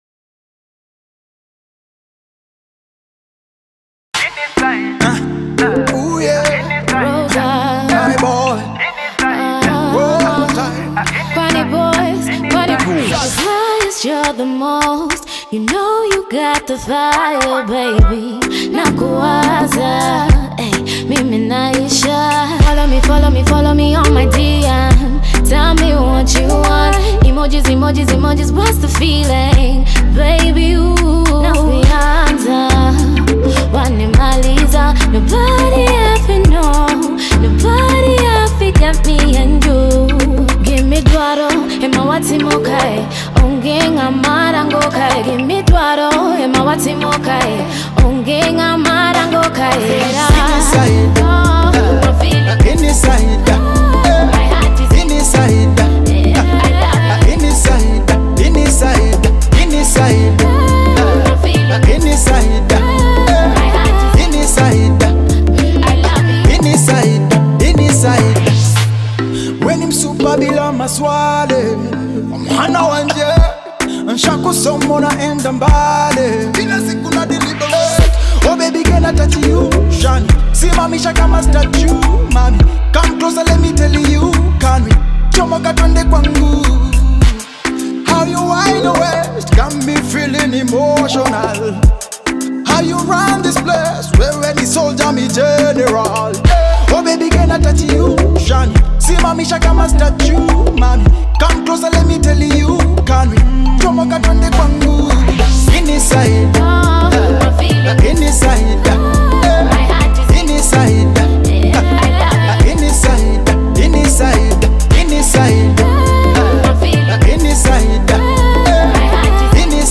urban pop smash love song